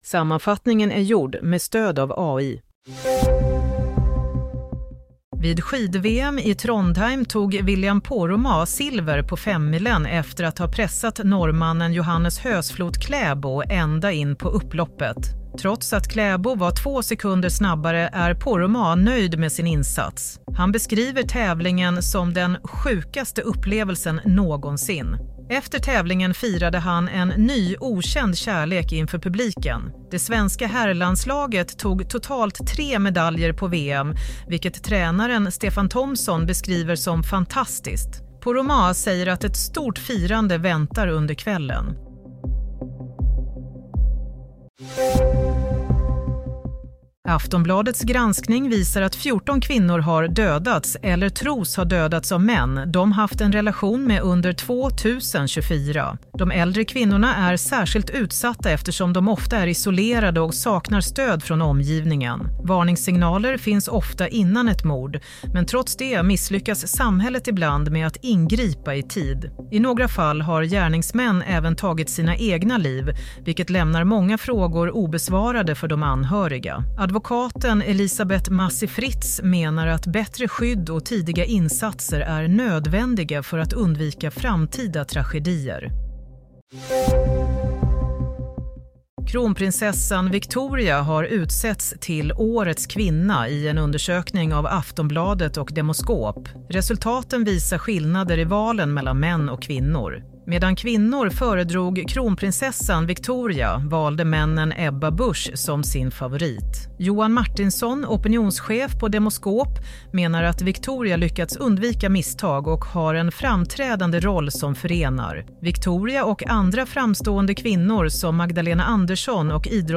Play - Nyhetssammanfattning – 8 mars 16:00